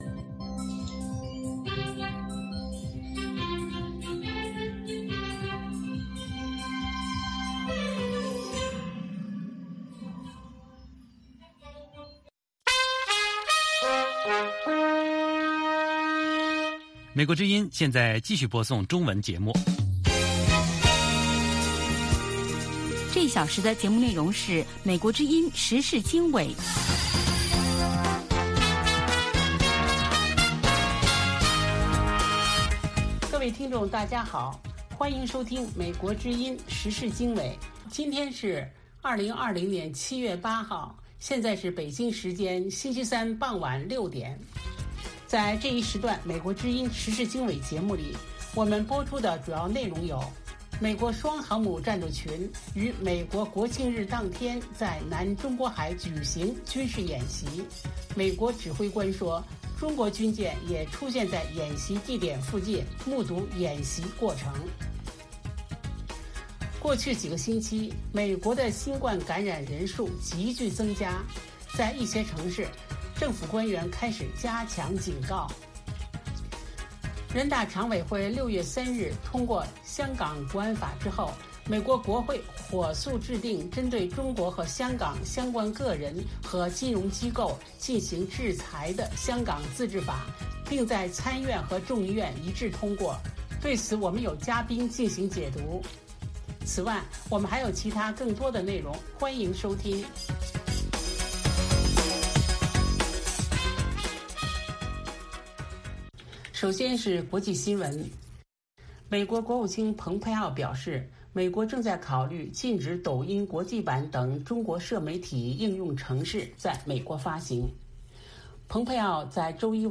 美国之音中文广播于北京时间每周一到周五晚上6-7点播出《时事经纬》节目。《时事经纬》重点报道美国、世界和中国、香港、台湾的新闻大事，内容包括美国之音驻世界各地记者的报道，其中有中文部记者和特约记者的采访报道，背景报道、世界报章杂志文章介绍以及新闻评论等等。